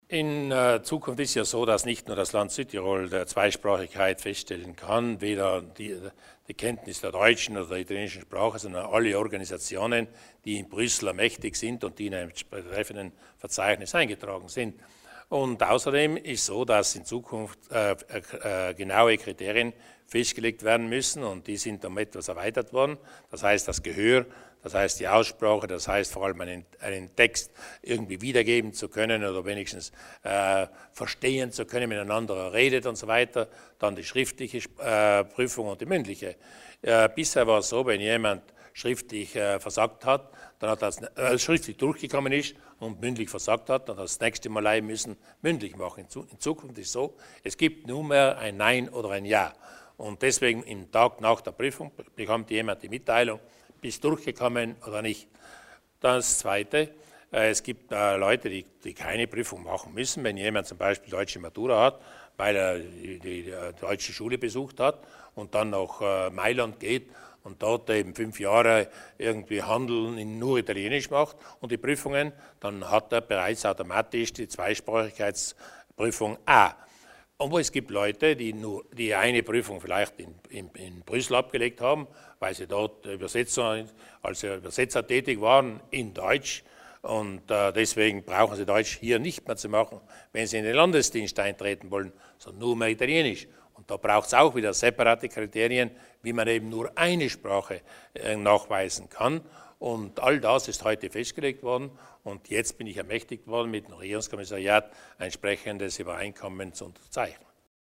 Landeshauptmann Durnwalder über die Neuheiten bei der Zweisprachigkeitsprüfung